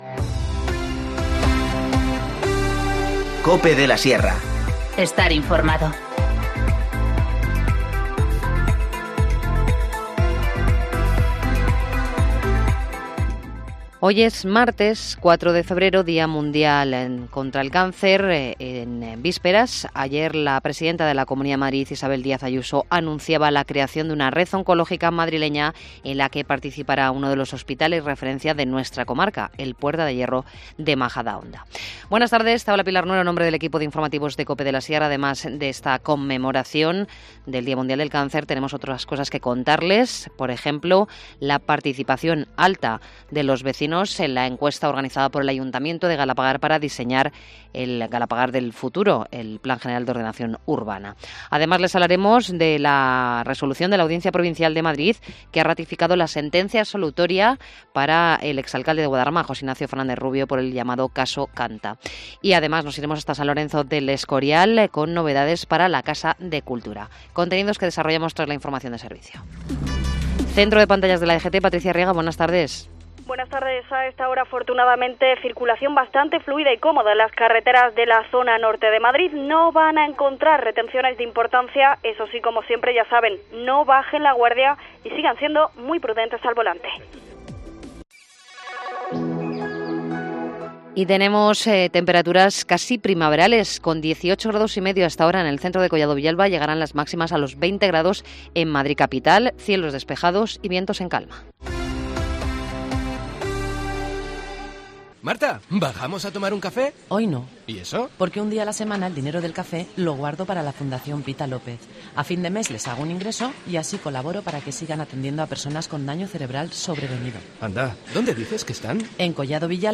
Informativo Mediodía 4 febrero 14:20h